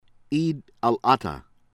IBROW, SALIM ALIYOW SAH-LEEM   AH-lee-oh   EEB-(uh)-roh